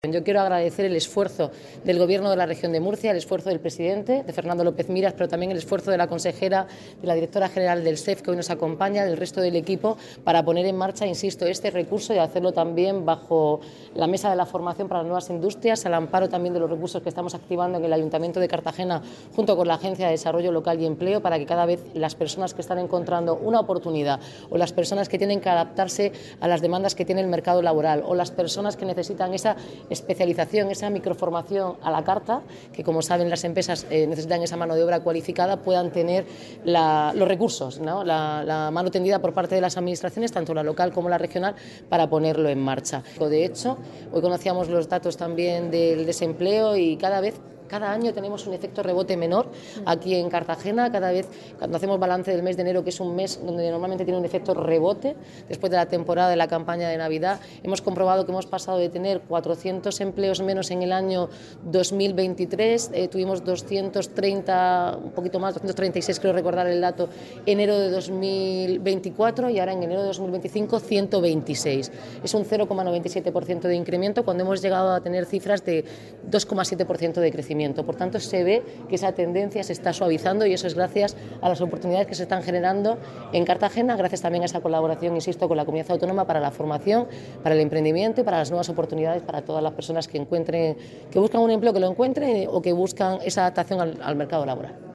Enlace a Declaraciones de la alcaldesa, Noelia Arroyo, en la inauguración del COE en San Félix